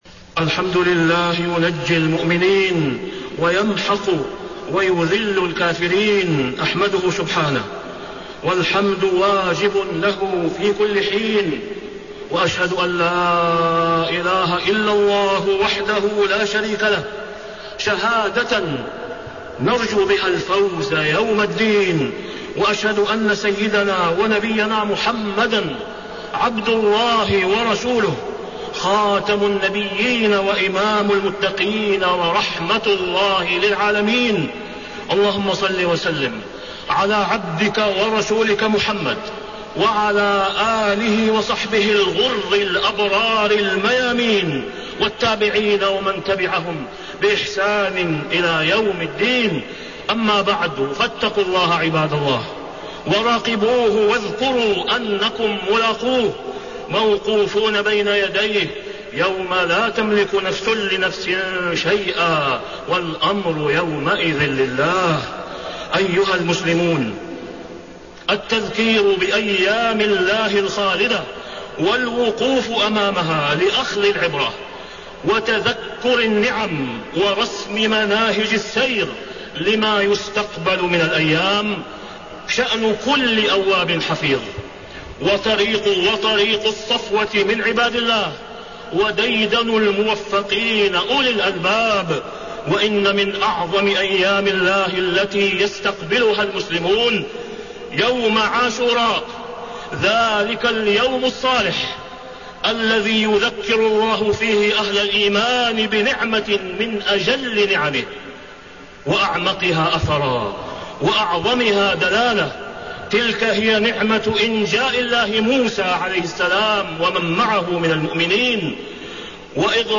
تاريخ النشر ٩ محرم ١٤٣٤ هـ المكان: المسجد الحرام الشيخ: فضيلة الشيخ د. أسامة بن عبدالله خياط فضيلة الشيخ د. أسامة بن عبدالله خياط قصة يوم عاشوراء